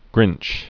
(grĭnch)